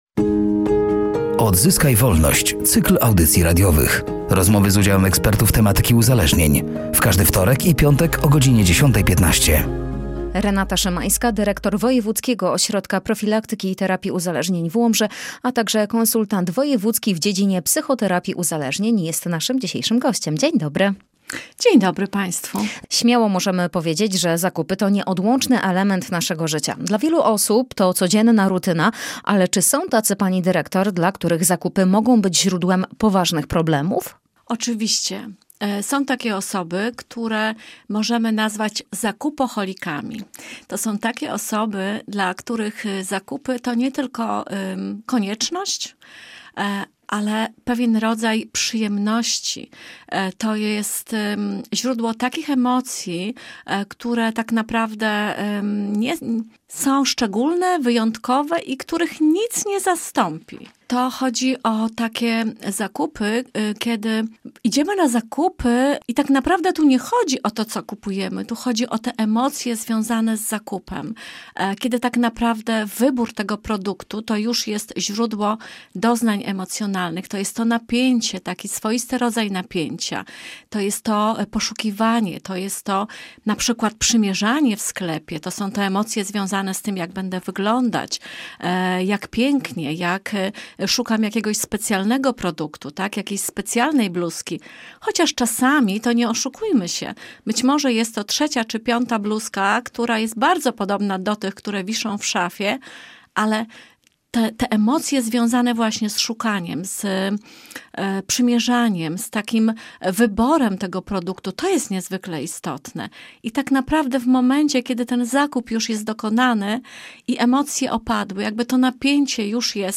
„Odzyskaj Wolność”, to cykl audycji radiowych poświęconych profilaktyce uzależnień wśród dzieci i młodzieży. W każdy wtorek i piątek o godzinie 10.15 na antenie Radia Nadzieja, eksperci dzielą się swoją wiedzą i doświadczeniem na temat uzależnień.